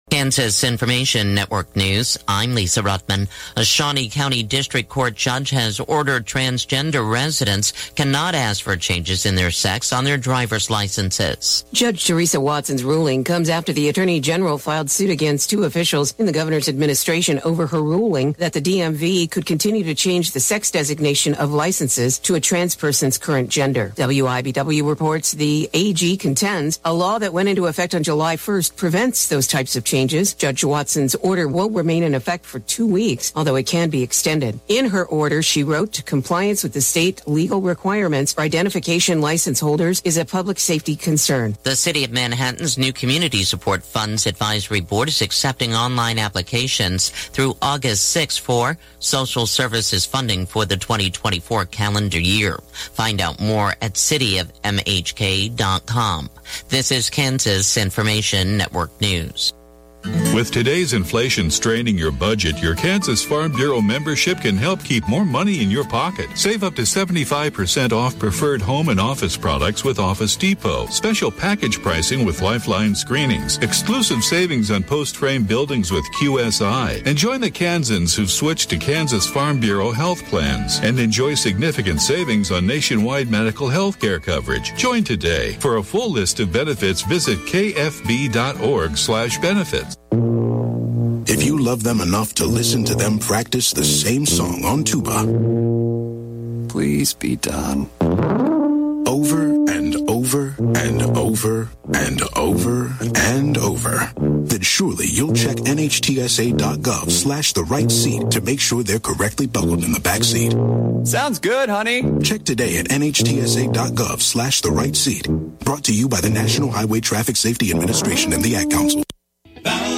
Classic Hits KQNK News, Weather & Sports Update – 7/11/2023